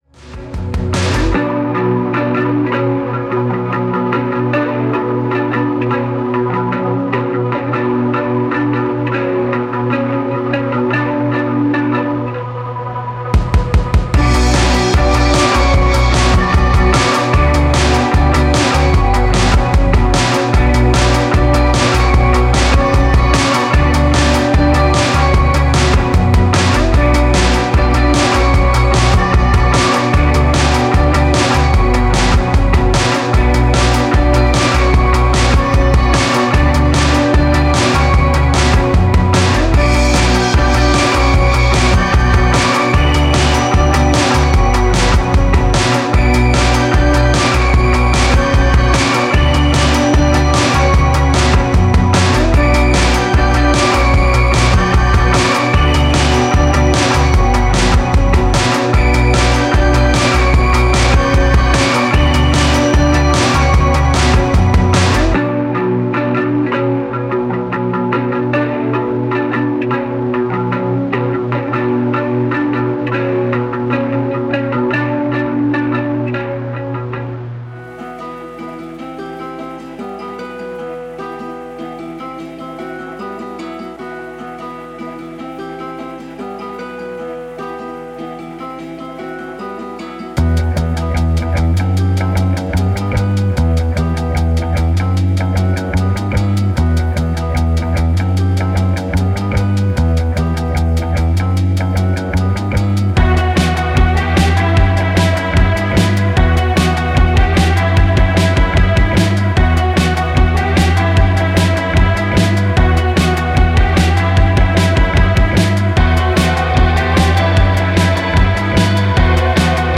メランコリーと静かな諦念の重みをまとっています。
デモサウンドはコチラ↓
Genre:Rock
90-170 BPM
20 Acoustic Guitar Loops
20 Piano Loops
15 Drum Loops